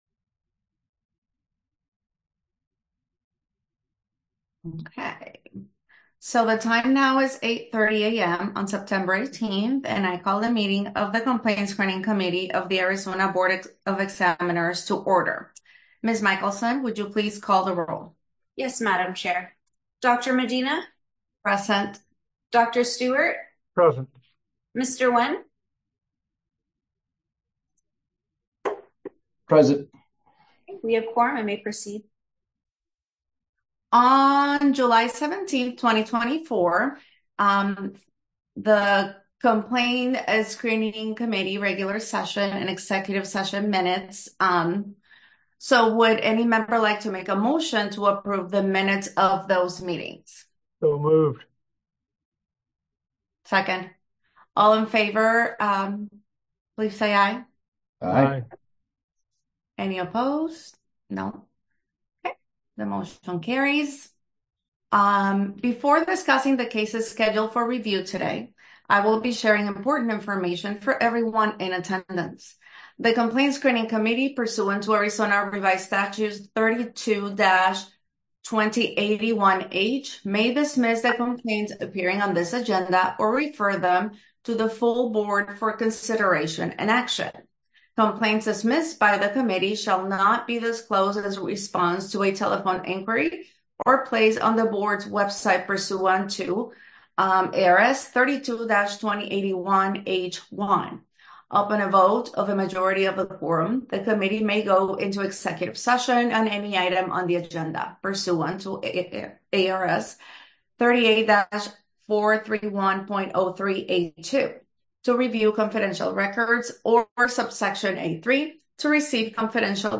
Complaint Screening Committee Meeting | Board of Psychologist Examiners
Members will participate via Zoom.